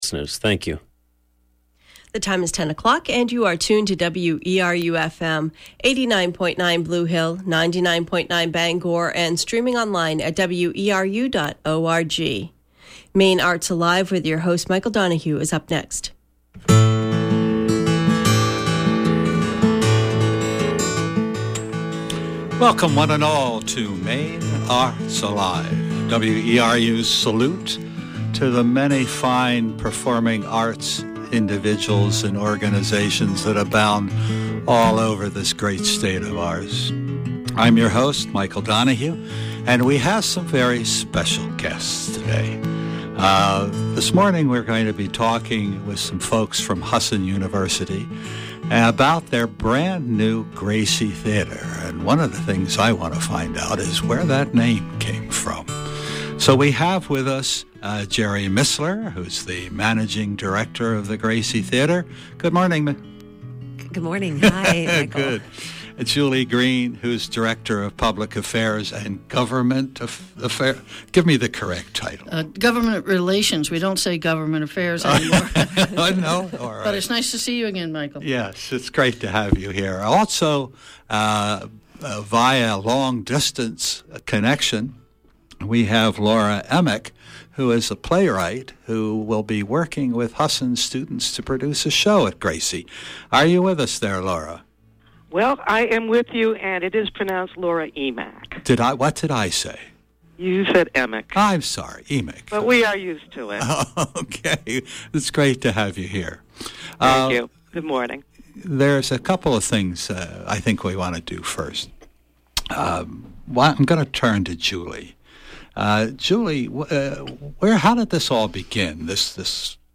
Playwright Call-In Program: yes